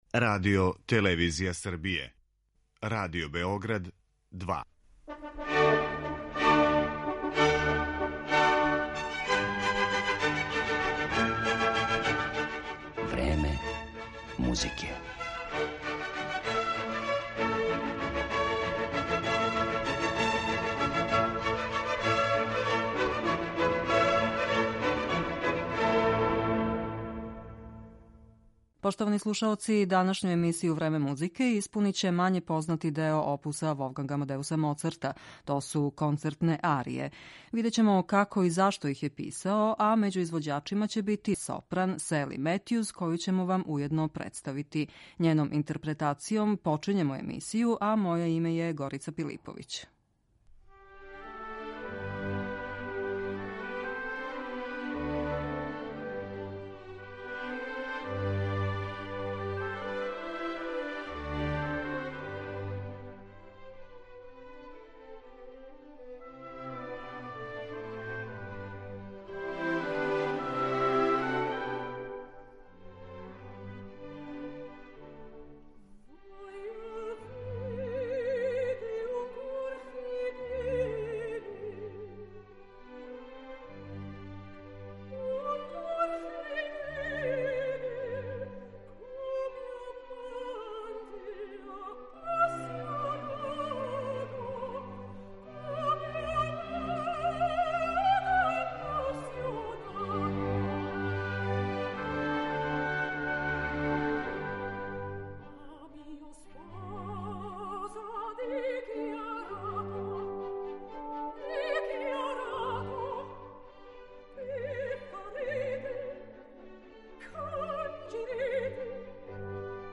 Данашњу емисију Време музике испуниће мање познати део опуса Волфганга Амадеуса Моцарта – то су концертне арије.